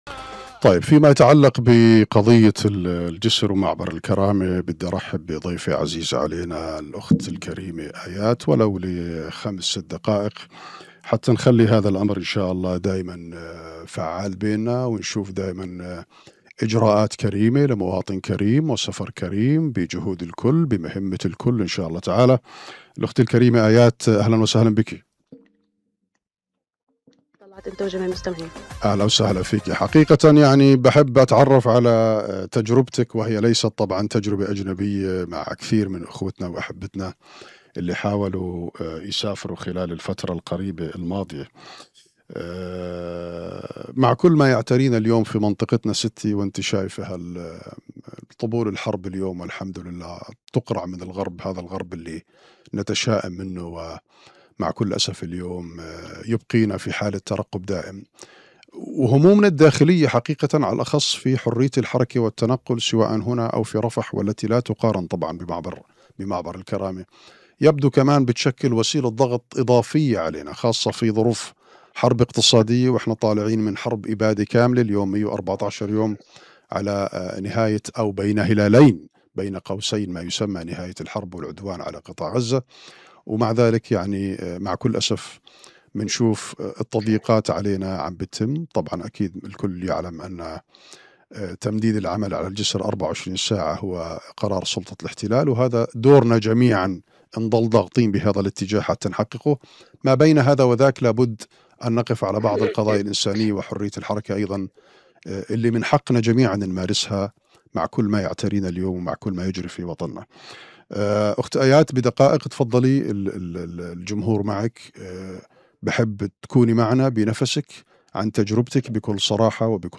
مواطنة فلسطينية